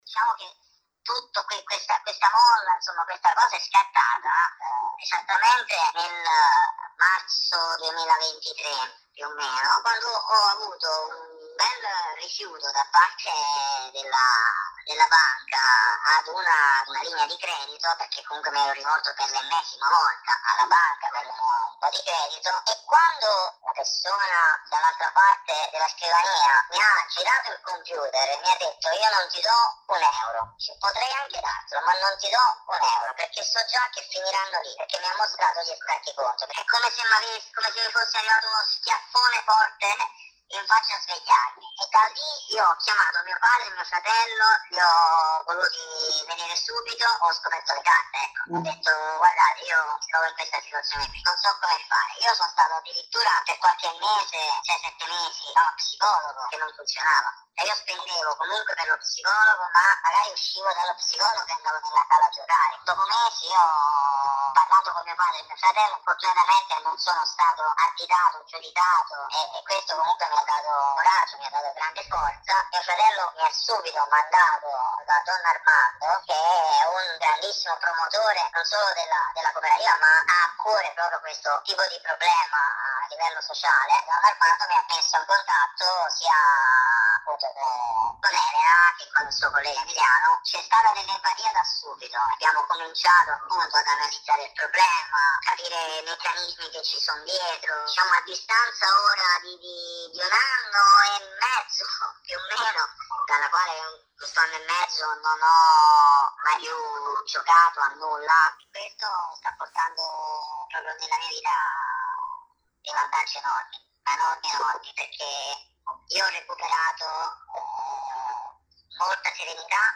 Questa la testimonianza.